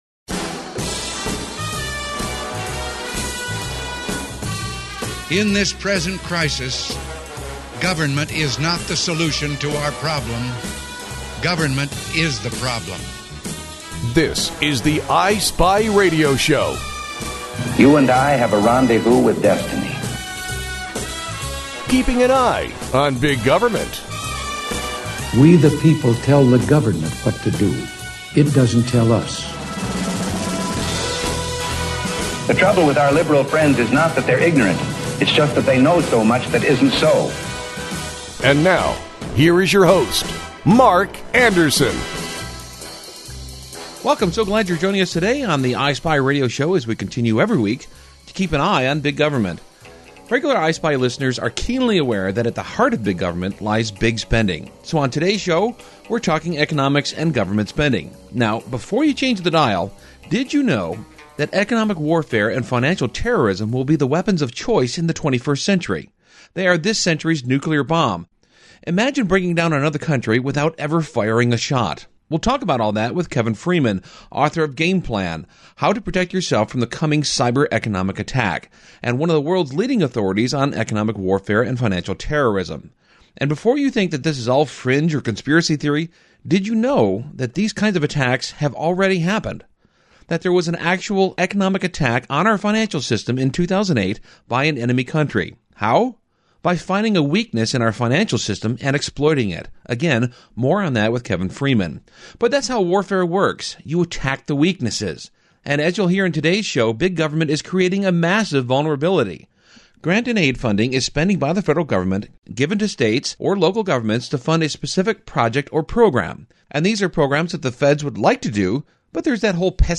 We talk about grant-in-aid funding with State Senator Doug Whitsett and how this funding is really about states giving up their own authority.